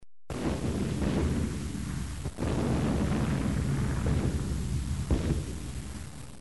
جلوه های صوتی
دانلود صدای بمب و موشک 6 از ساعد نیوز با لینک مستقیم و کیفیت بالا